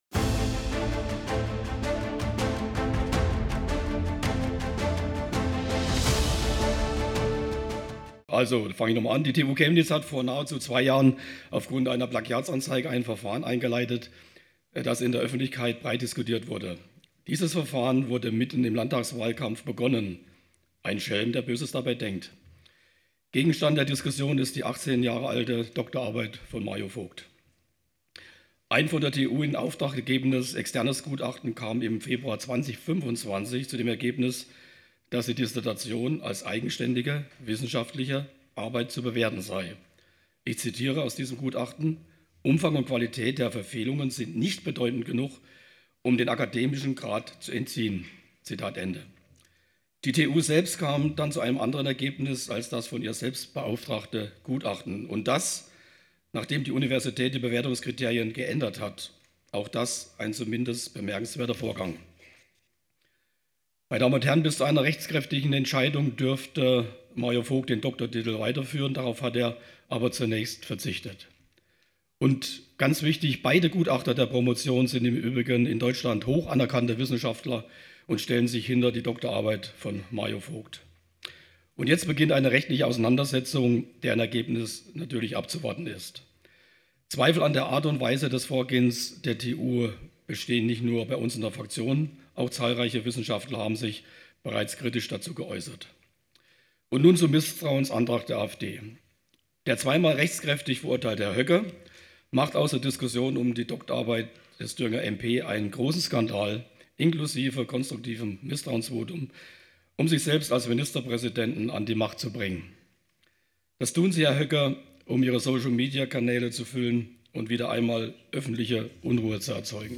Der Bericht aus Erfurt dokumentiert an dieser Stelle die Rede von Dr. Frank Augsten (BSW) in der Landtagsdiskussion vor der Abstimmung.....